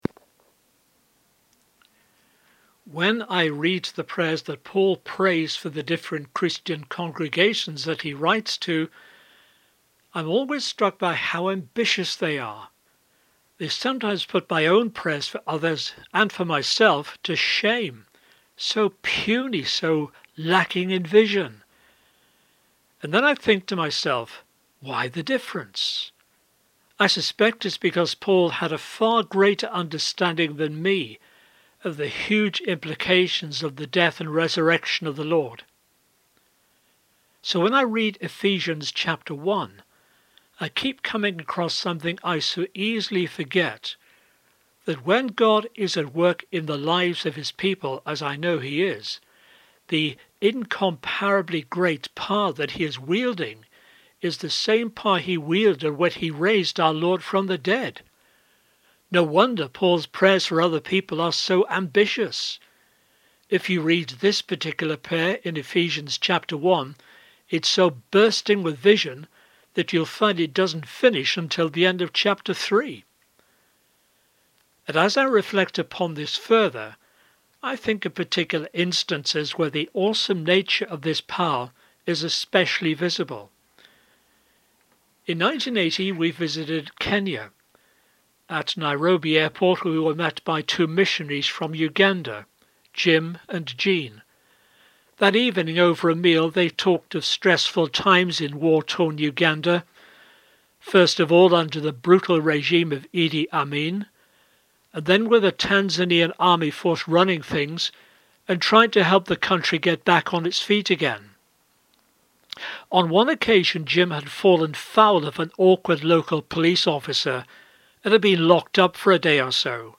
Friday 9 April Easter Reflection